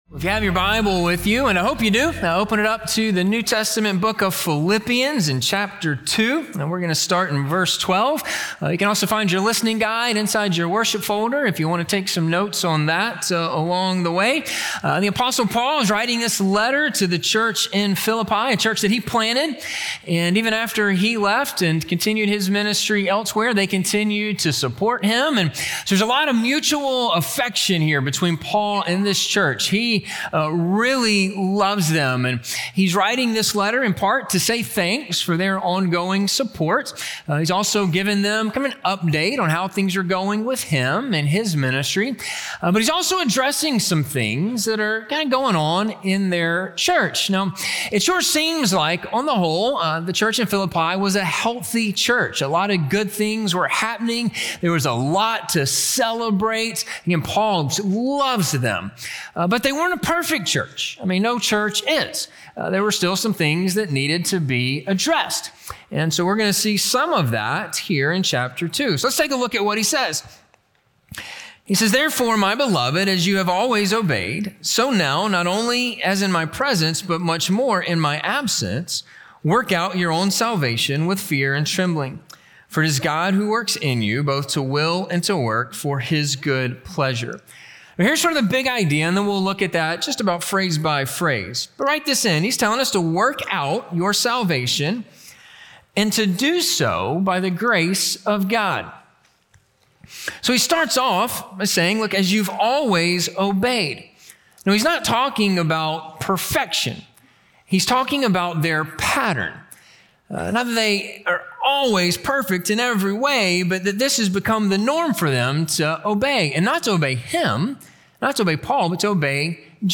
Work Out Your Salvation - Sermon - Ingleside Baptist Church